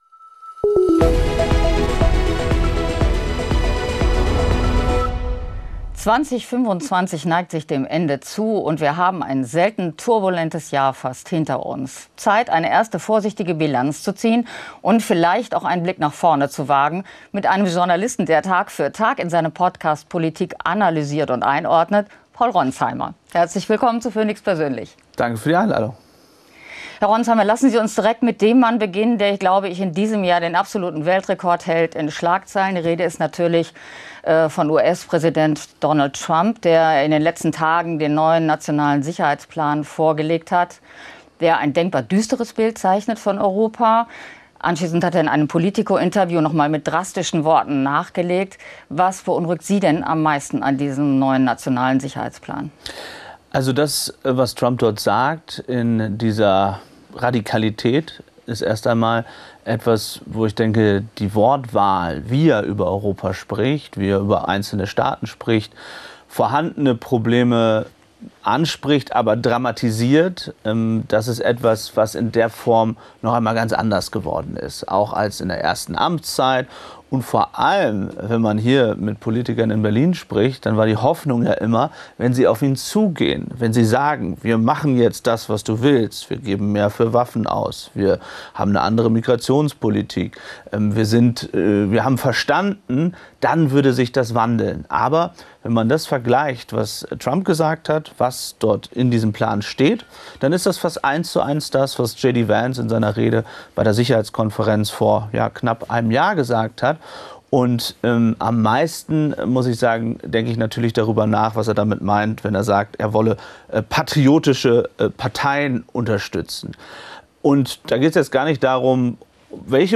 Über seine Erfahrungen und Arbeit in Kriegsgebieten, vor allem in der Ukraine, und über die politischen Schlagzeilen des Jahres erzählt Paul Ronzheimer im Gespräch